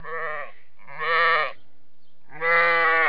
جلوه های صوتی
دانلود صدای بره برای کودکان از ساعد نیوز با لینک مستقیم و کیفیت بالا
برچسب: دانلود آهنگ های افکت صوتی انسان و موجودات زنده